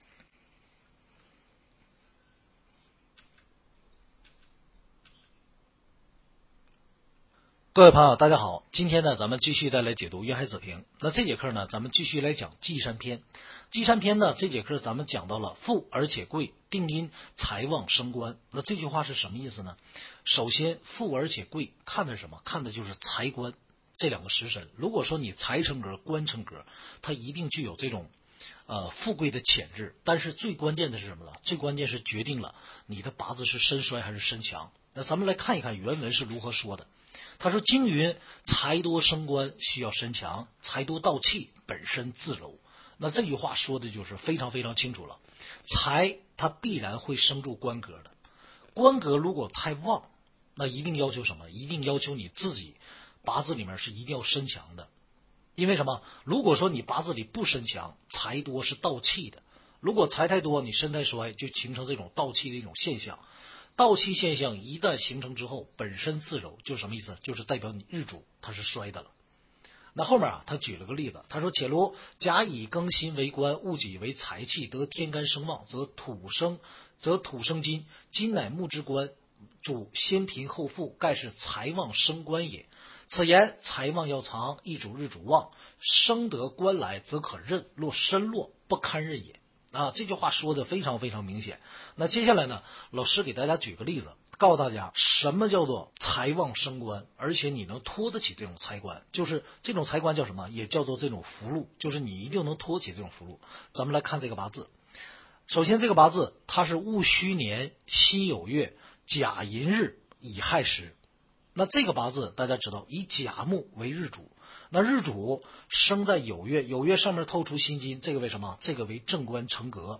听书渊海子平白话文